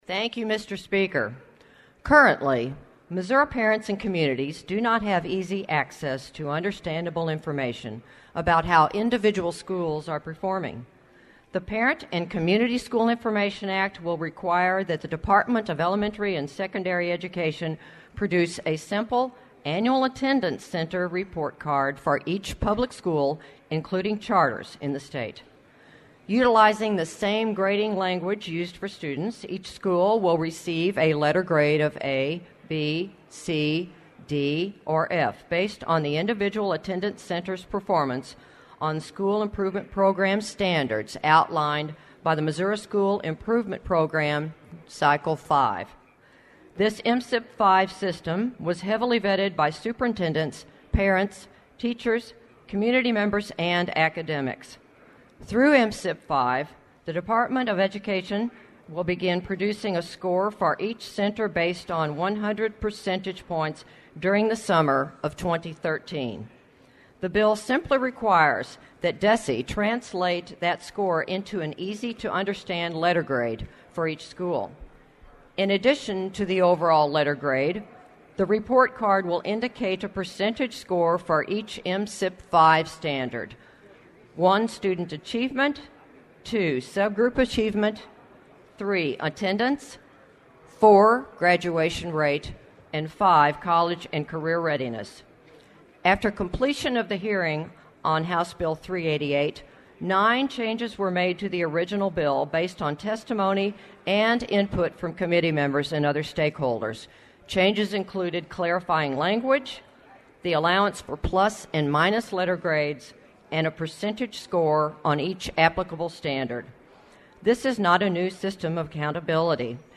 AUDIO:  Rep. Kathryn Swan presents HB 388, 3:13
Kathryn-Swan-presents-HB-388.mp3